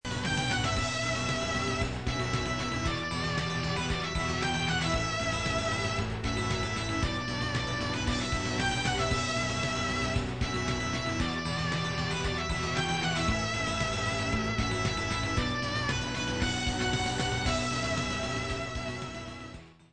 制作環境（ハード）：Windows機内蔵音源
試聴環境：X-68030 ＋ SC88VL